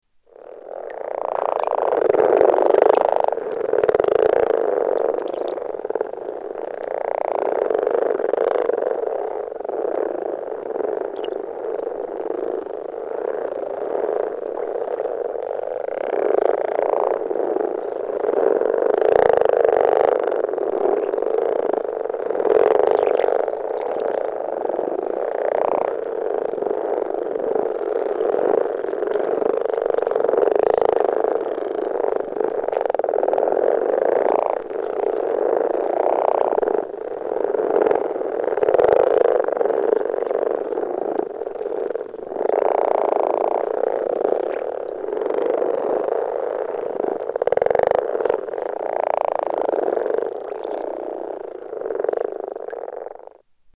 Frogs in Mooste